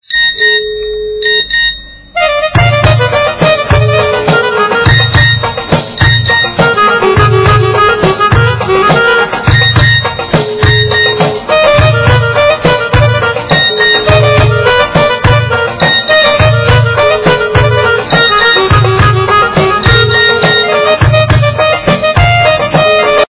звуки для СМС